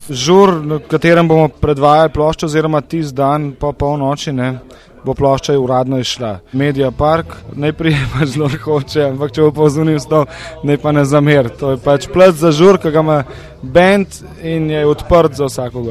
Posneto v Orto Baru 23.5.2006 na predposlušanju nove plošče Siddharte.